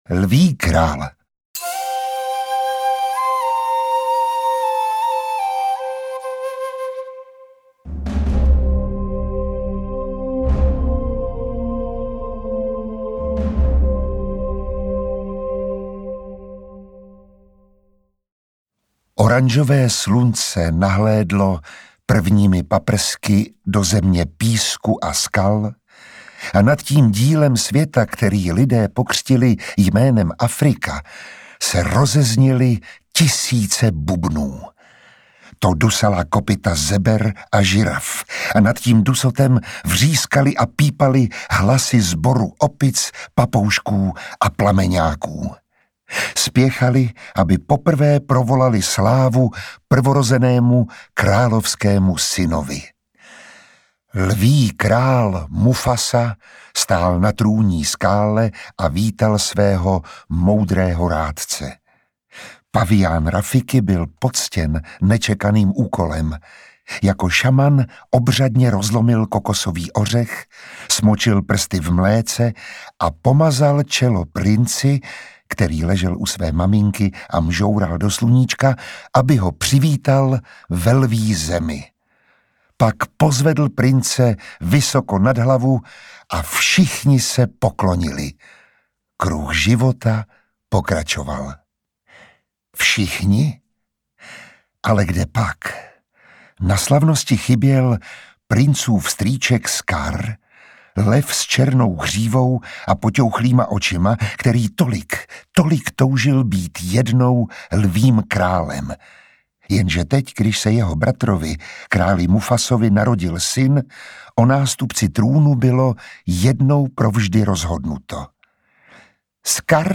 Interpret:  Miroslav Táborský
AudioKniha ke stažení, 1 x mp3, délka 31 min., velikost 28,5 MB, česky